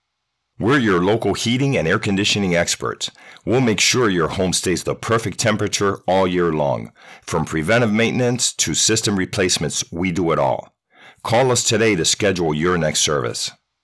From warm to authoritative, I bring the right tone every time—tailored to connect with your audience.
Commercial HVAC